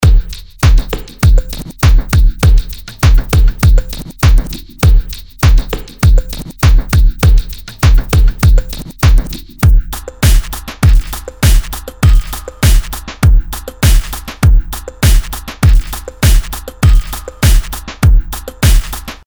Diesen Beat habe ich nun seiner Original-Bassdrum beraubt und lasse die Bassdrum statt dessen durch die PunchBox spielen. Dazu habe ich per Zufallsgenerator einen Sound herbei geklickt.
Ist schon ganz schön satt, was die PunchBox da liefert – und ohne überhaupt tiefer eingestiegen zu sein, wünscht man sich gleich eine HitBox für Snare- und Tomsounds dazu.